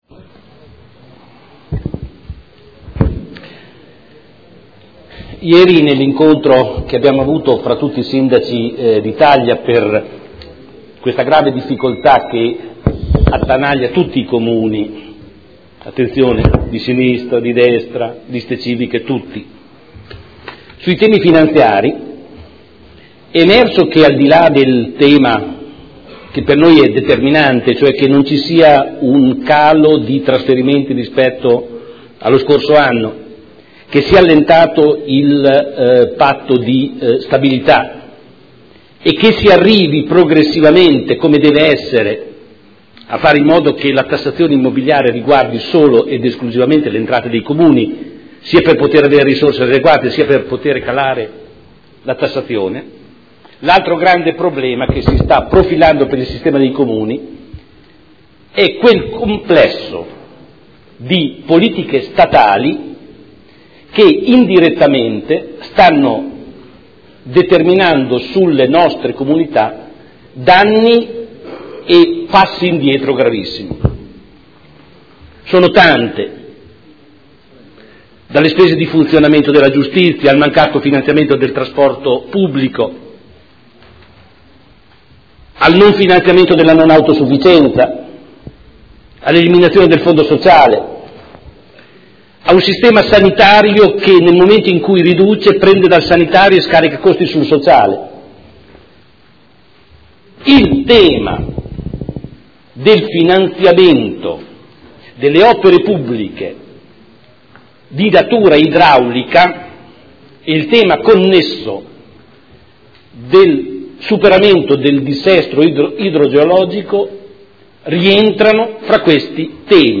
Seduta del 30/01/2014. Dibattito su interrogazioni riguardanti l'esondazione del fiume Secchia.